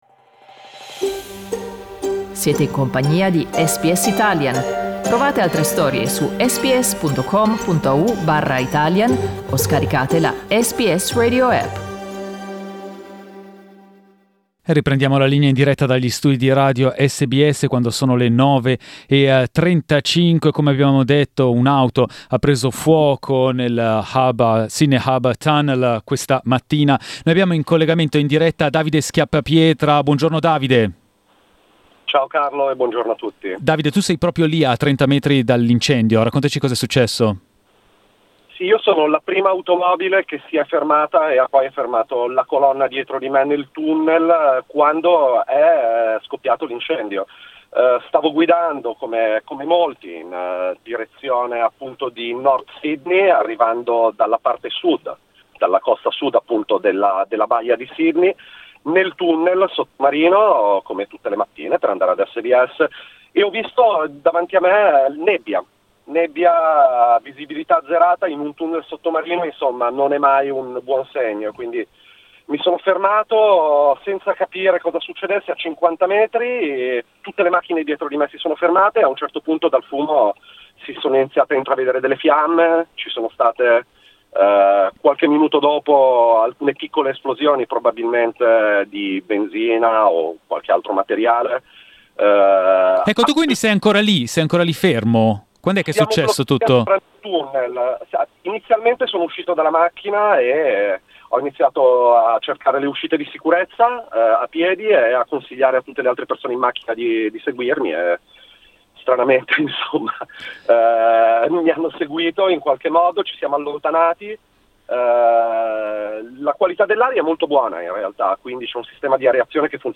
During the live program on Tuesday 25 August, we received the news of a car fire in the Sydney Harbour tunnel.
Listen to the interview in Italian: